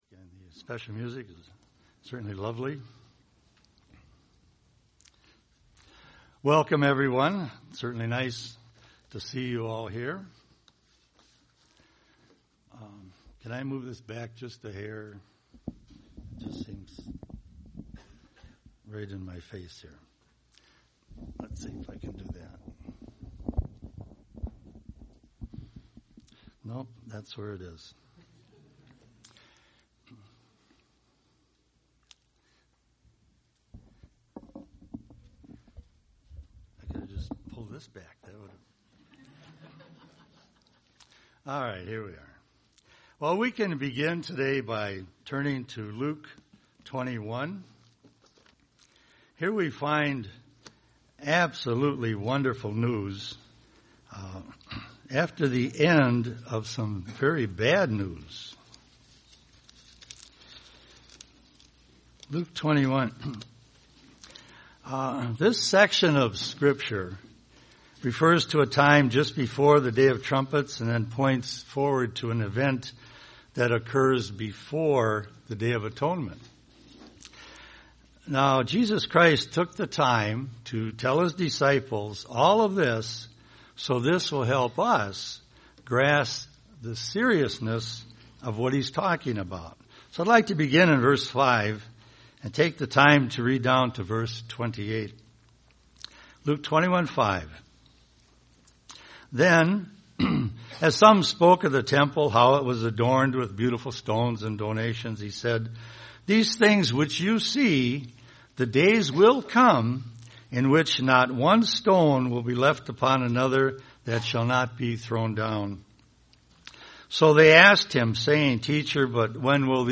Given in Twin Cities, MN
UCG Sermon Feast of Trumpets Studying the bible?